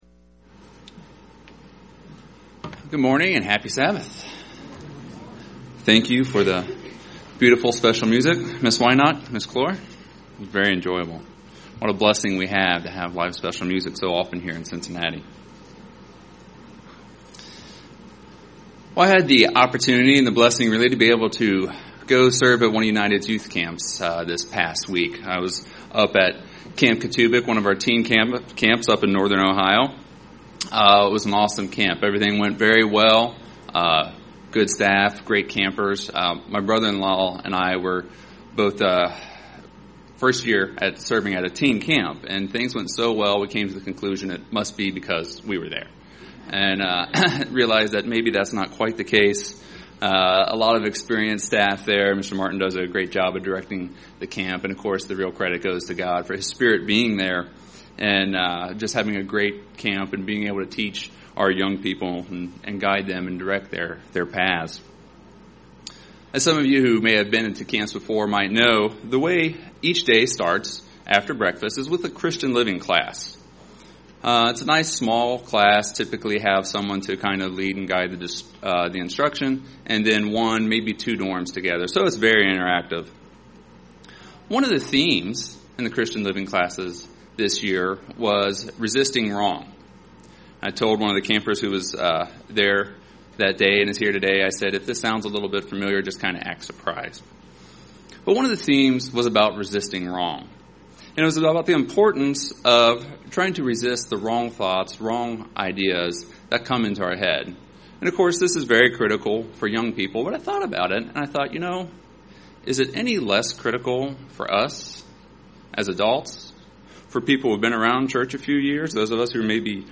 Given in Cincinnati East, OH
UCG Sermon Studying the bible?